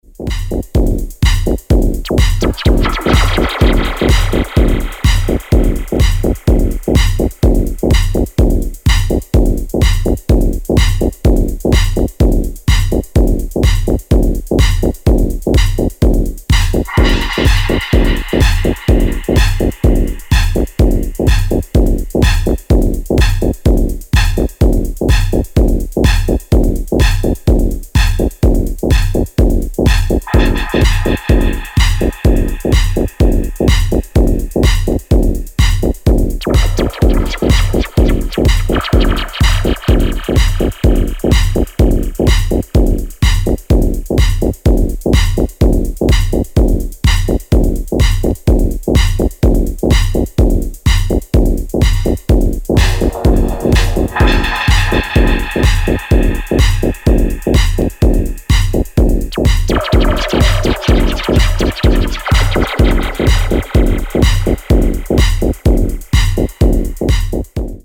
Club ready bangers.